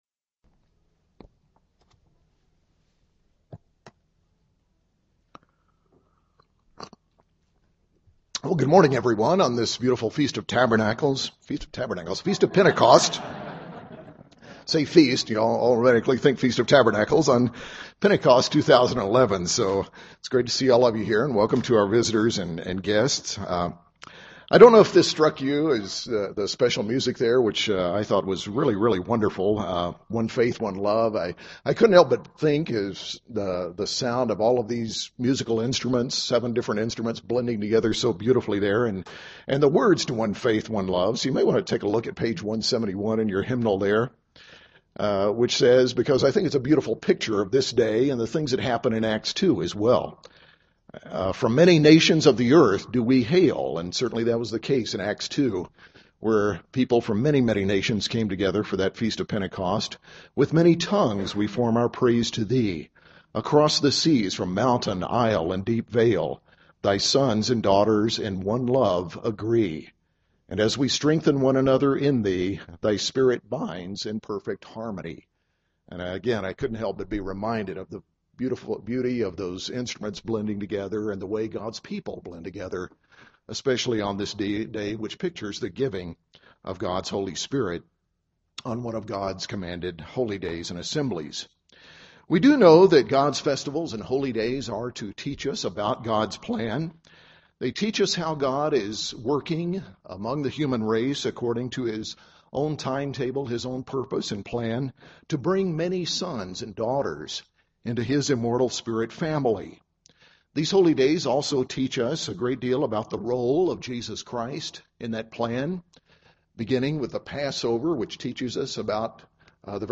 In this sermon we'll look at Scripture and history to examine four great lessons we can learn from the Feast of Pentecost.